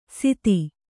♪ siti